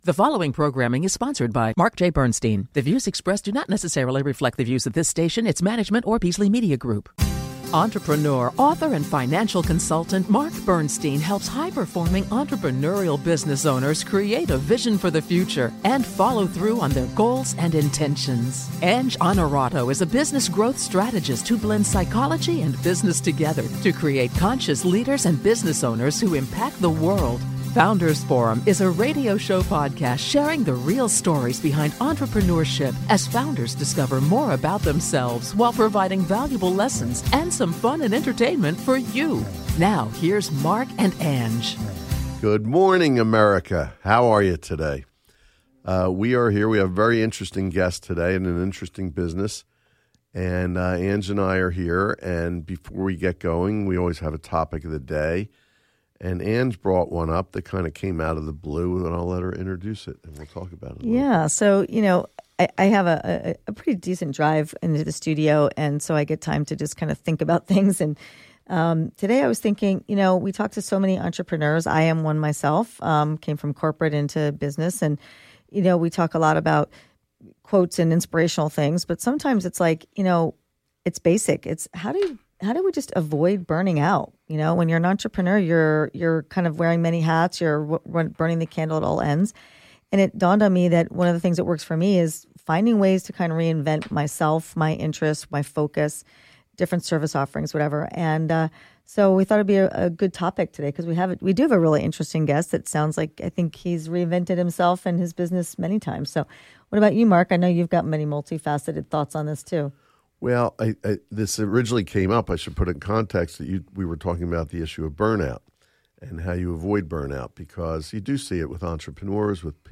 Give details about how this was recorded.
Founders’ Forum: Wednesday at 10am on WWDB 860 AM